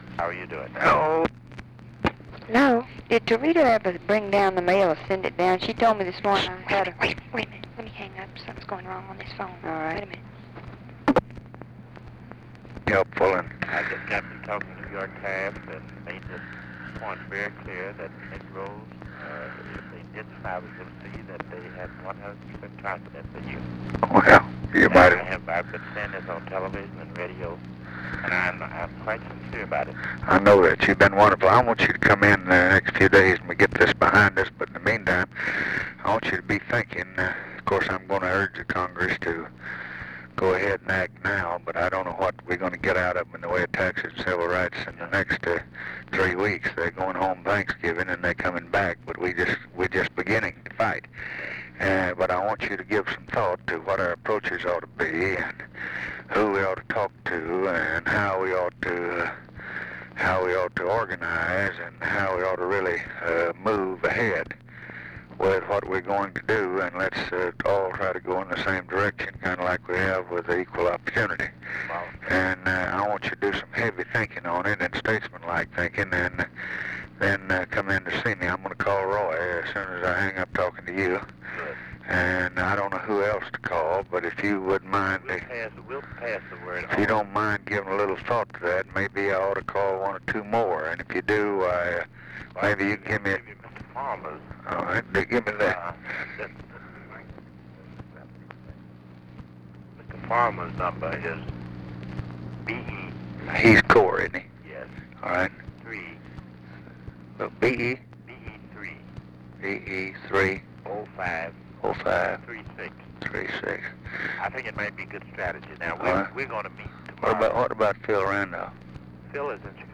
Conversation with WHITNEY YOUNG, November 24, 1963
Secret White House Tapes